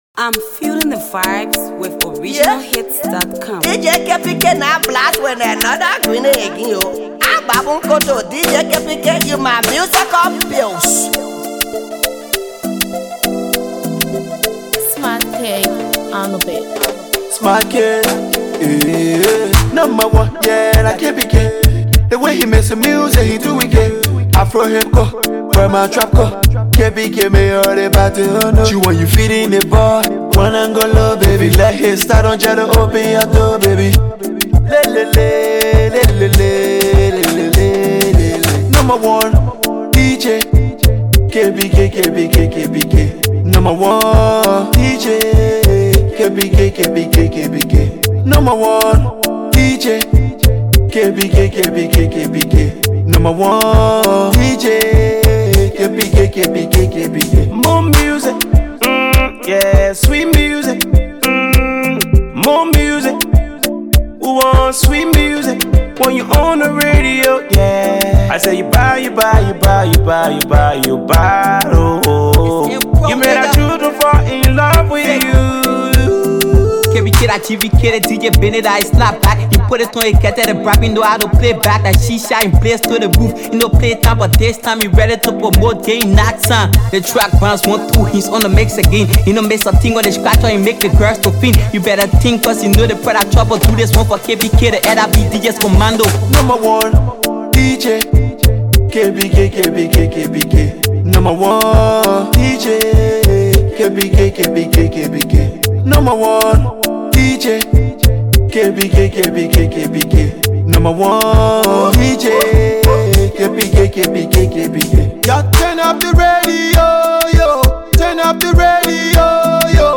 Afro Afro Pop Hipco Music